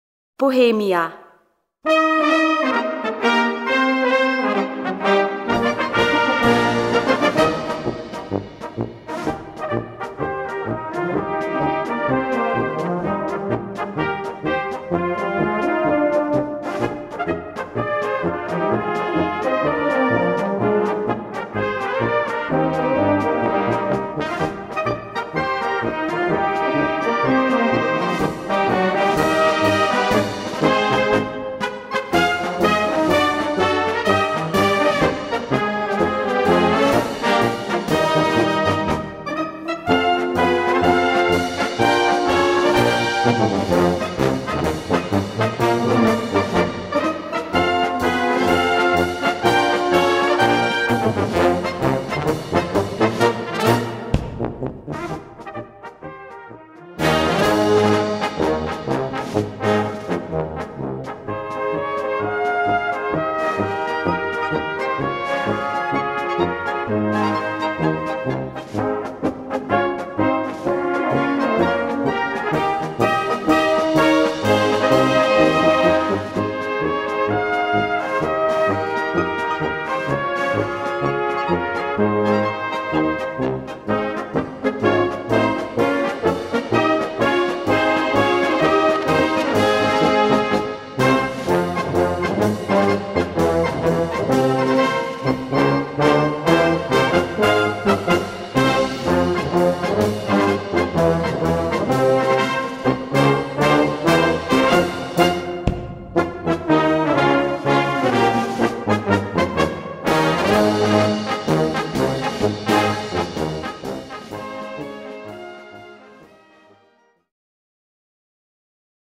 Gattung: Marsch
Besetzung: Blasorchester
Konzertmarsch